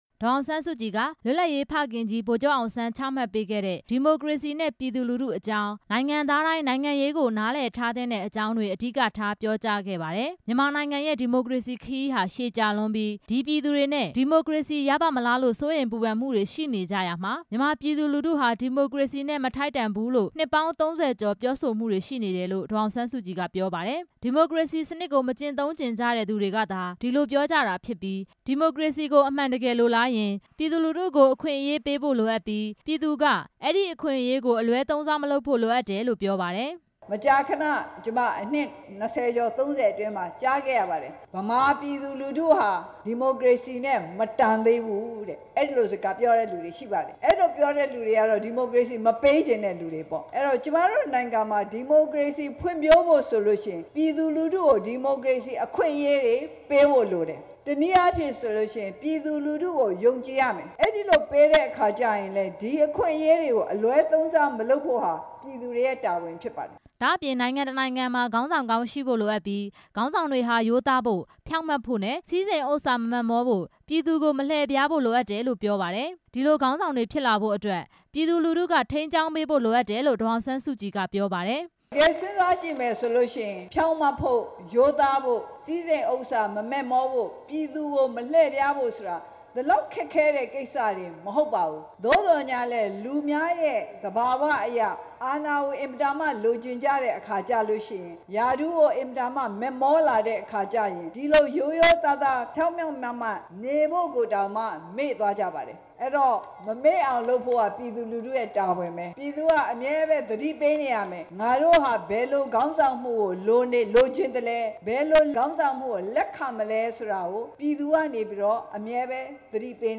ဒီမိုကရေစီ မကျင့်သုံးချင်သူတွေ အကြောင်း ဒေါ်အောင်ဆန်း စုကြည် ဟောပြော
ဒေါ်အောင်ဆန်းစုကြည်ရဲ့ ပြောကြားချက်များ
ပဲခူးတိုင်းဒေသကြီး ရွှေမော်ဓေါစေတီ အနောက်ဘက် မုဒ်က ကွင်းထဲမှာ ဒီနေ့ကျင်းပတဲ့ ဗိုလ်ချုပ်အောင် ဆန်း နှစ်တစ်ရာပြည့်အကြိုပွဲမှာ ဒေါ်အောင်ဆန်း စုကြည်က အခုလို ပြောလိုက်တာပါ။